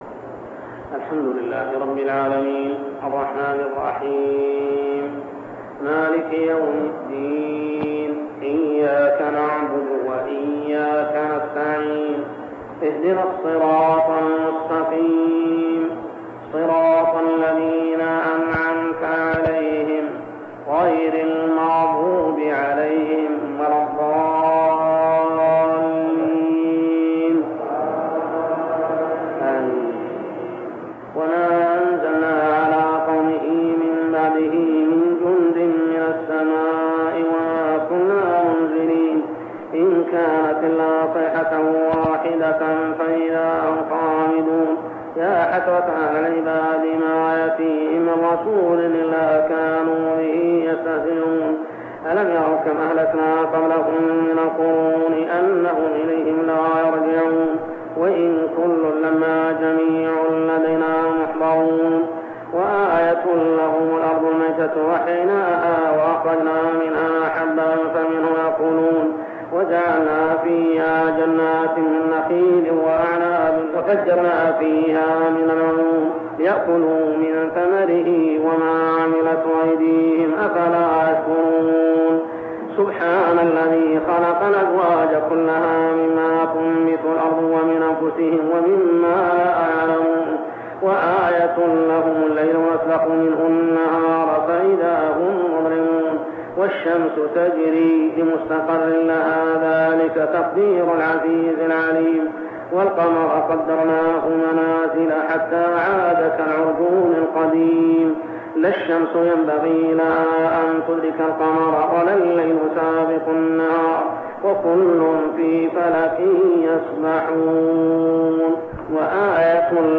صلاة التراويح عام 1402هـ سورتي يسٓ 28-83 و الصافات 1-148 | Tarawih prayer Surah Yasin and As-Saffat > تراويح الحرم المكي عام 1402 🕋 > التراويح - تلاوات الحرمين